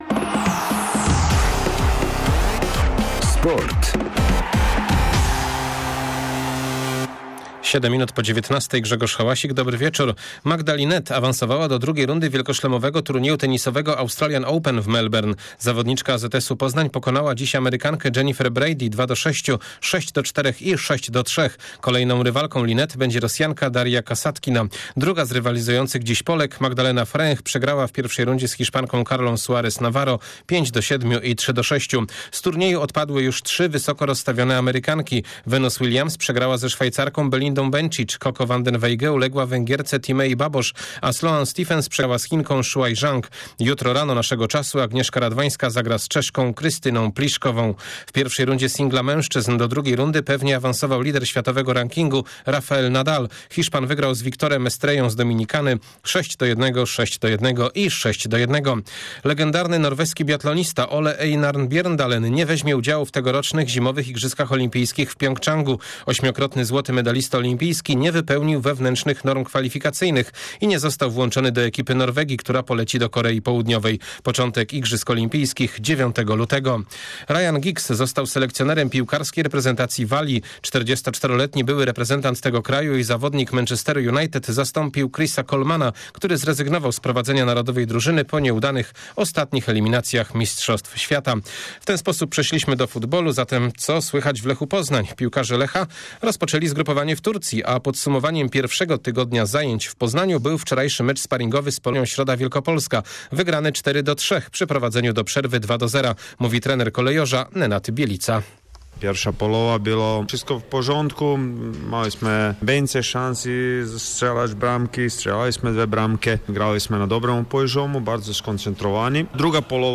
15.01 serwis sportowy godz. 19:05